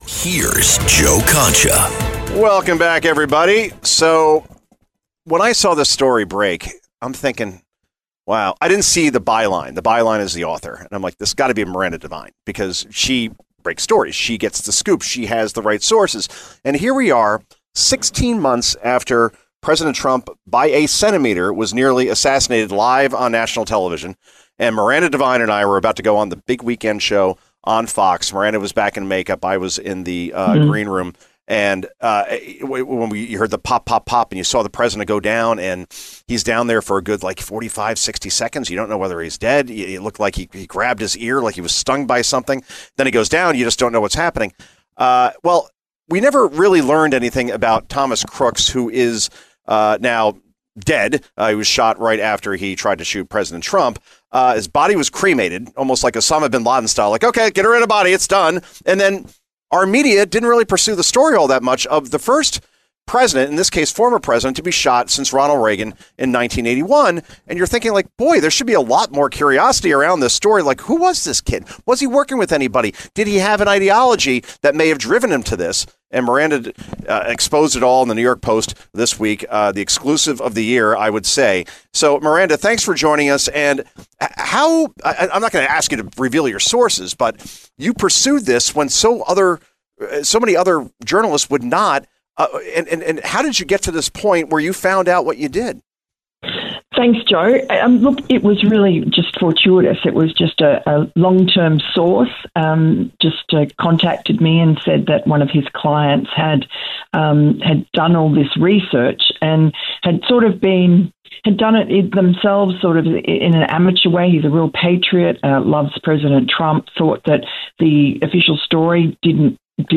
Sharp Analysis, Bold Commentary, Real Conversation
With a reputation for cutting through media clutter, Concha delivers unfiltered insight, tough questions, and a take-no-prisoners style that keeps listeners tuned in.
During this week’s broadcast, Concha and Devine discussed a deeply personal and provocative story: a young man, Thomas Crooks, who attempted to assassinate former President Trump, a conversation loaded with emotional and political weight.